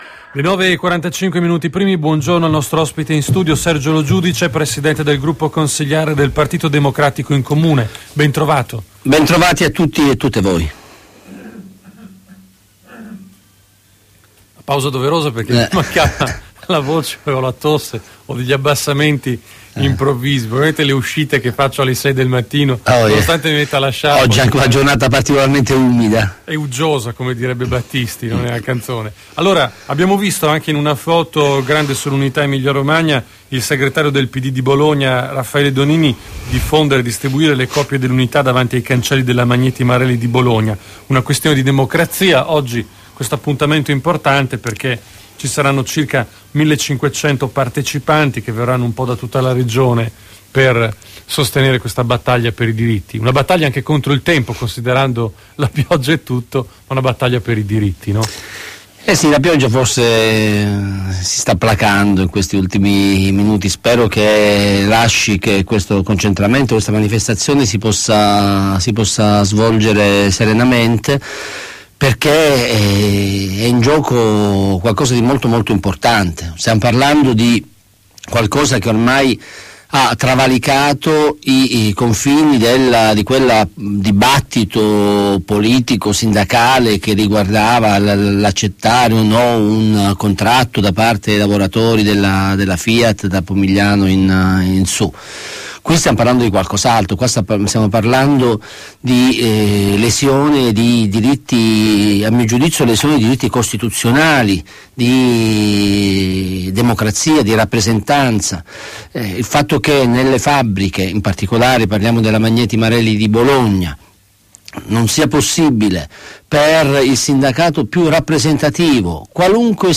Intervista a Radio Tau del capogruppo PD Sergio Lo Giudice il 6 marzo 2012
Il Presidente del gruppo consiliare PD Sergio Lo Giudice fornisce gli ultimi aggiornamenti sui principali temi d'attualità politica nell'intervista effettuata durante la trasmissione Detto tra noi